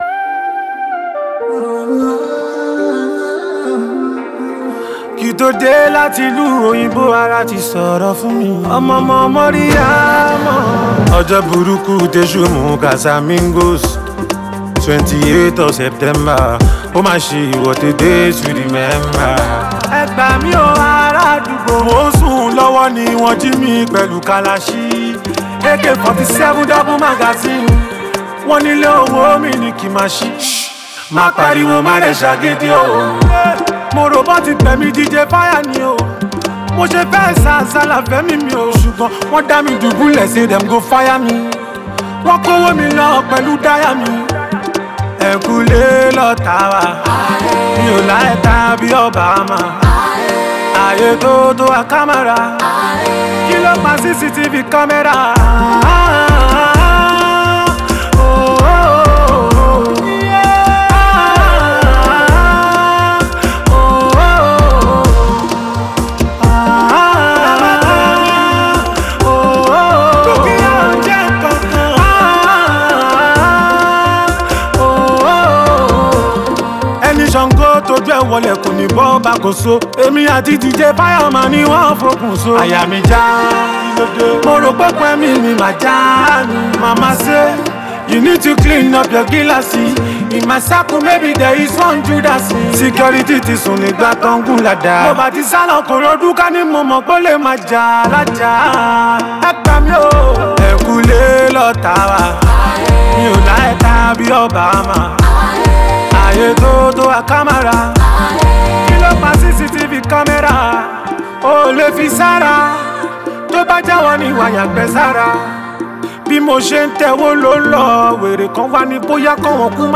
A Must-Listen Afrobeat Jam
Nigerian singer and Afrobeat sensation